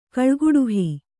♪ kaḷguḍuhi